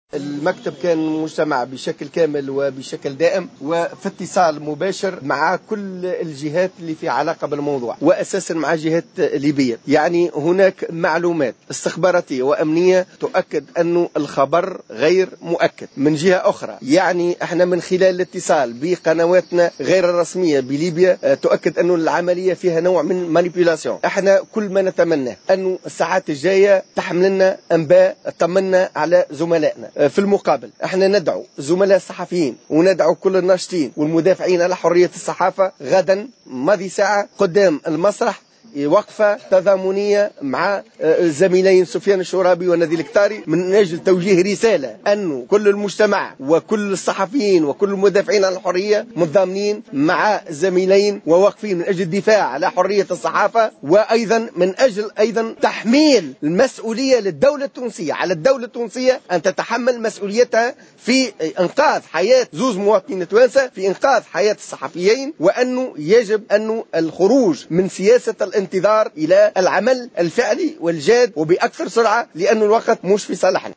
تصريح إعلامي